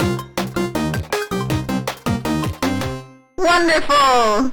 victory_dog.ogg